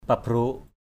/pa-bro:ʔ/ (đg.) xóc = enfourcher. pabrok pong F%_bK _pU xóc rơm = prendre la paille avec une fourche et la secouer pour faire tomber le riz.